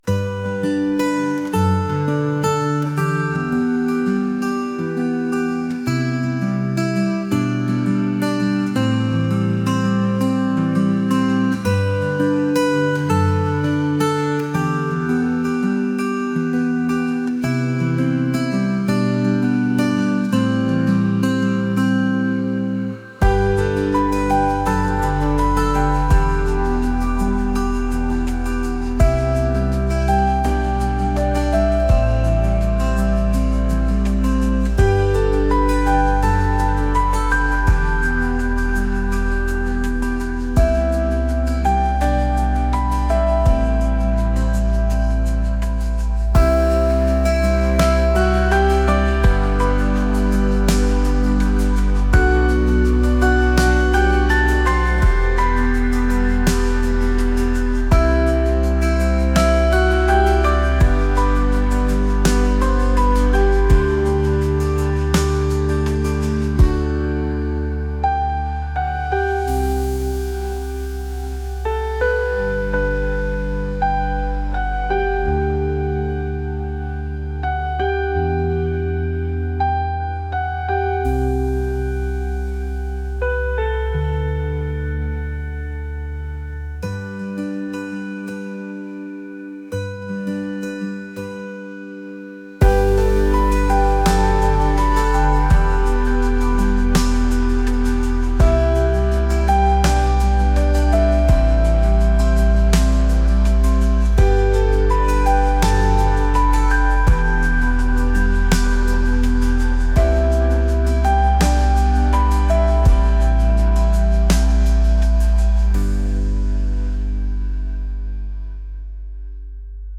pop | atmospheric | dreamy